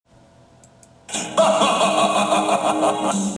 Sound Bytes from the Konami X-men Video Game
(Evil laughter)
Magneto_Evil_Laughter.wav